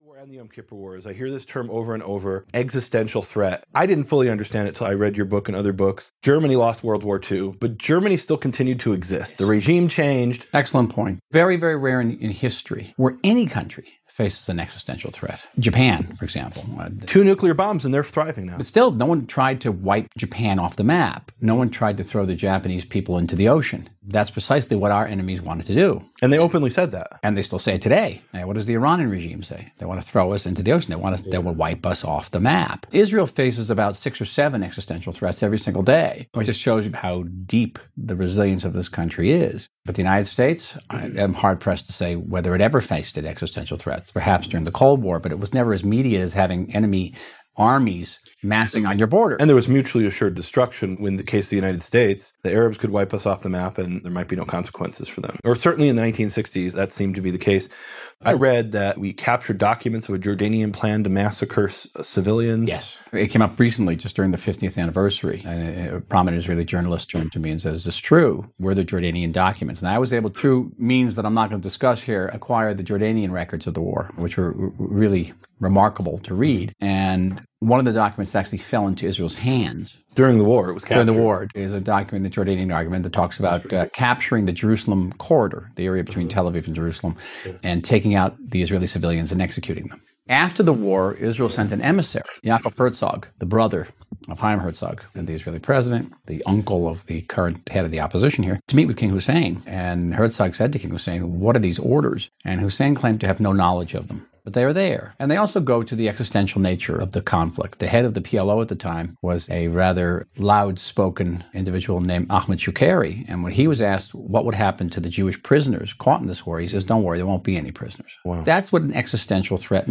In this extract, Michael Oren explains clearly what an existential threat means.